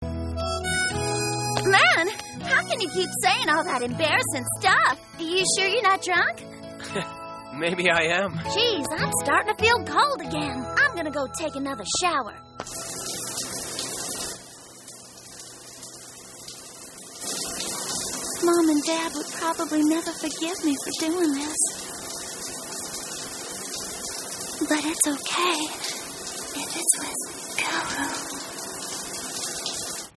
うれしいので、発表された声優さんが他の作品で演じた声を聴きながらどんな感じになるのか想像してみました。